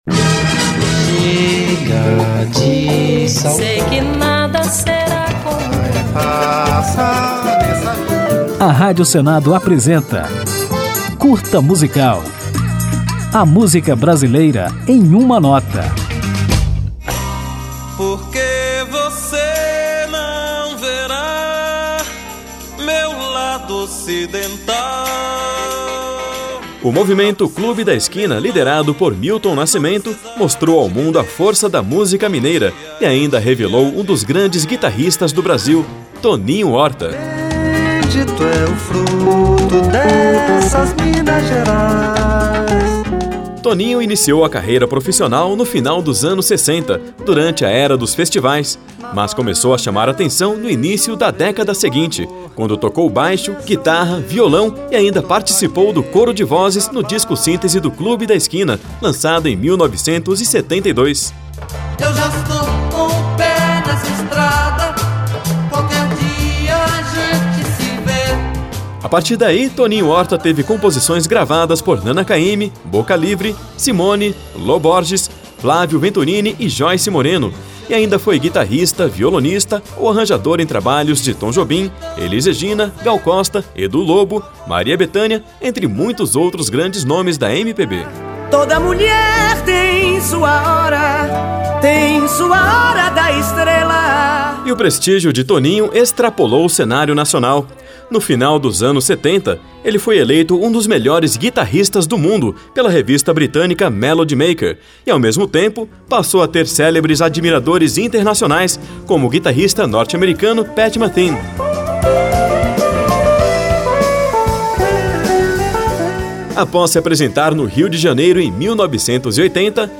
Conjunto de pílulas radiofônicas sobre a MPB, nas quais o ouvinte pode conferir fatos, curiosidades, informações históricas e ainda ouvir uma música ao final de cada edição.